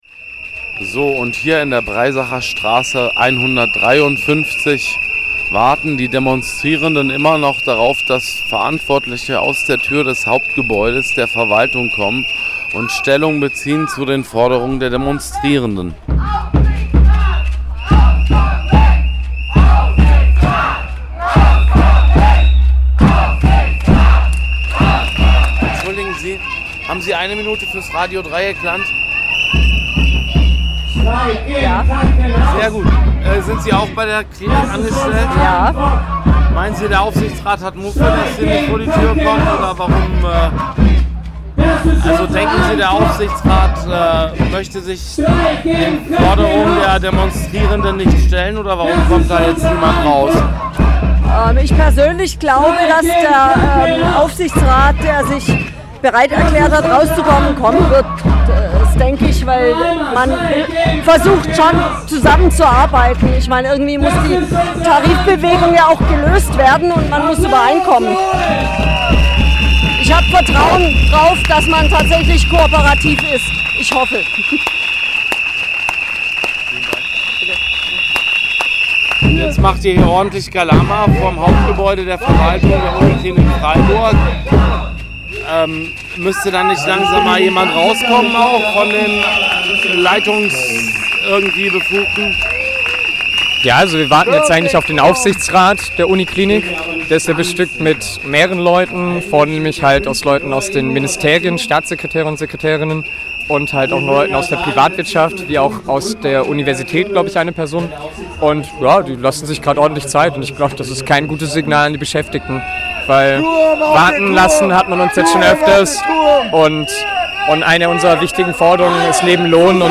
Vor dem Gebäude, in dem der Aufsichtsrat tagt, sammelt sich die Demonstration und wird warten gelassen: